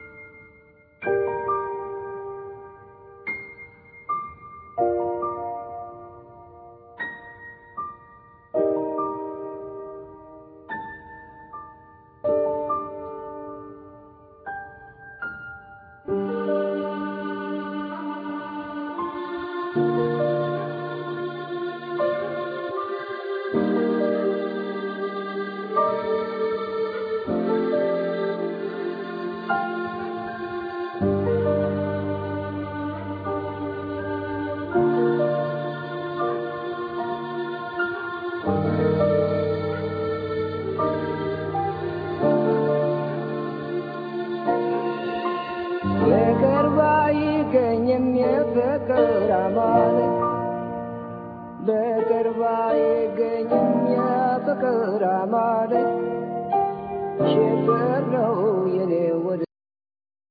Piano,Electronics
Bass,Effects,Beats
Vocals,Bodhran,Percussions,Darbuka,Tin whistle
Harp,Sensuational backing vocals
Oud